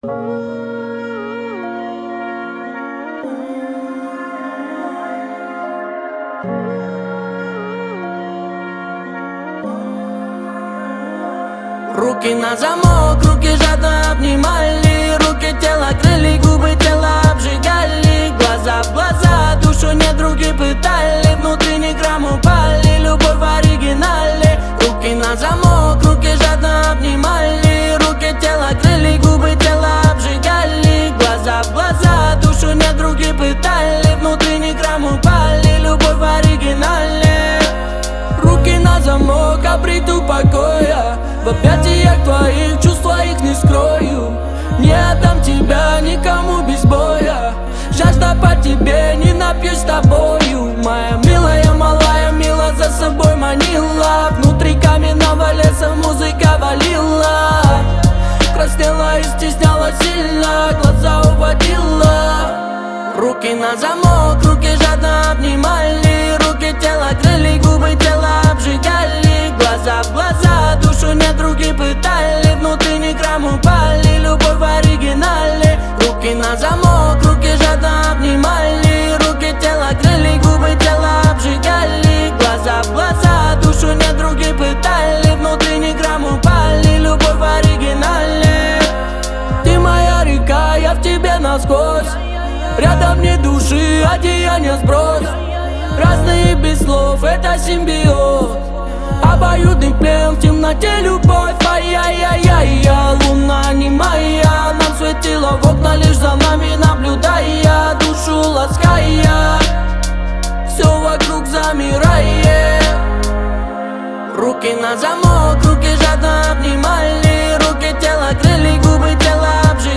динамичная и энергичная песня